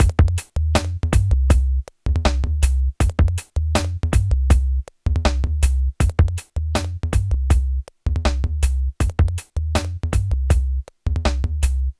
Downtempo 25.wav